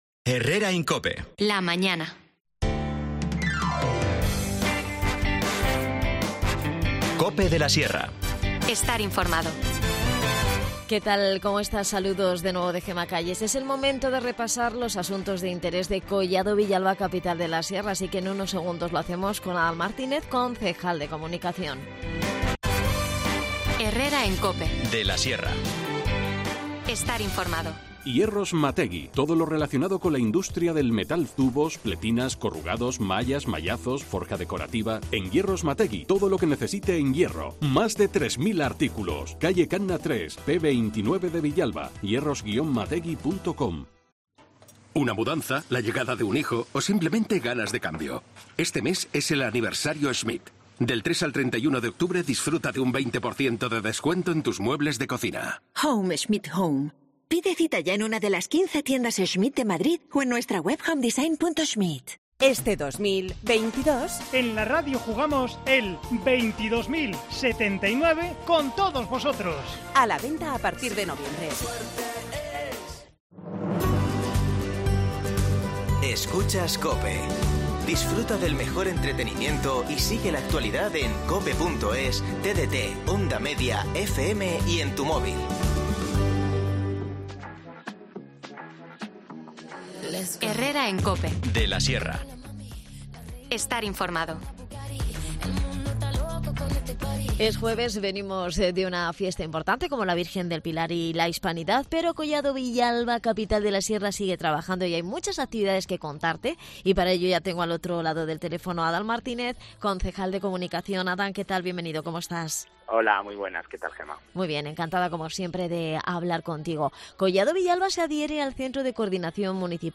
De este y otros asuntos hemos hablado con Adan Martínez, concejal de Comunicación.